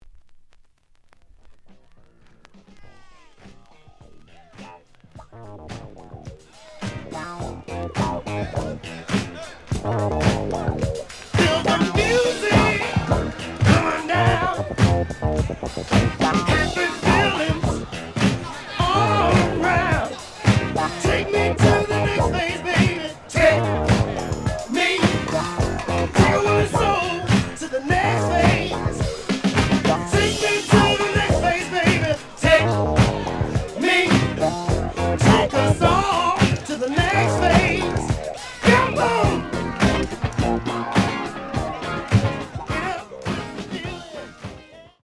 The audio sample is recorded from the actual item.
●Genre: Funk, 70's Funk
Edge warp. But doesn't affect playing. Plays good.)